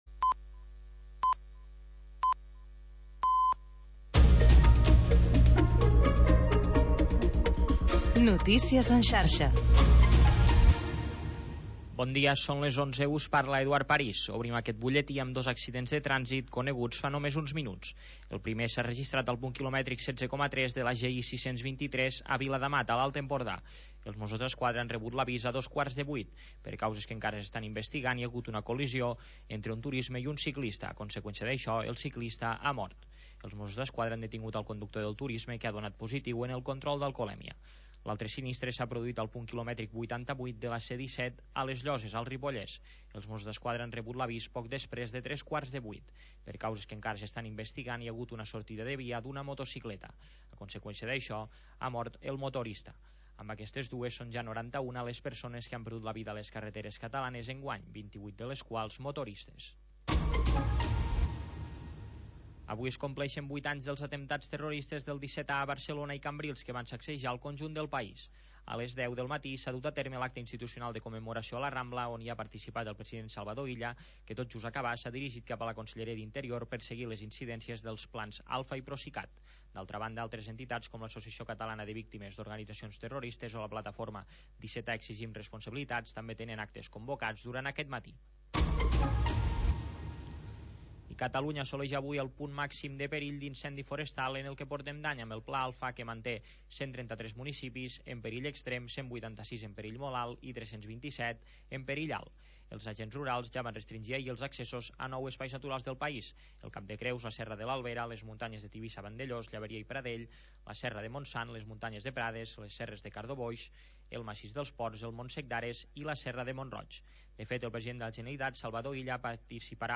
Havanera, cant de taverna i cançó marinera. 15 anys obrint una finestra al mar per deixar entrar els sons més mariners